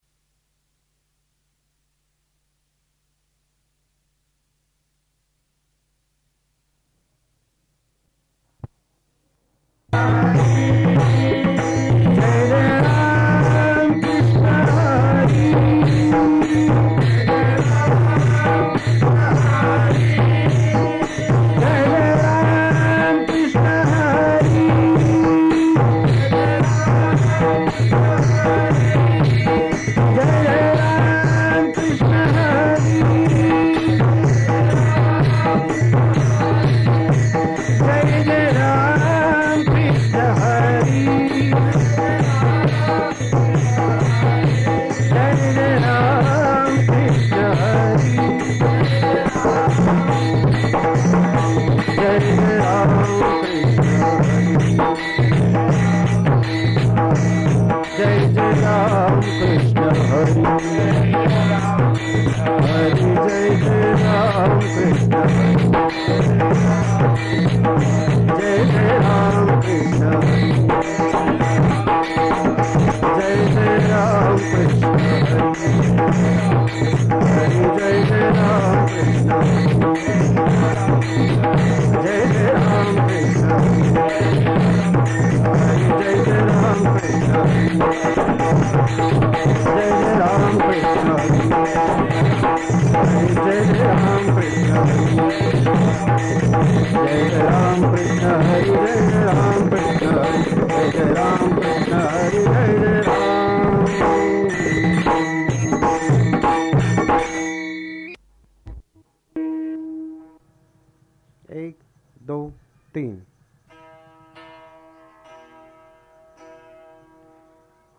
भजन - अभंग श्रवण
पखवाज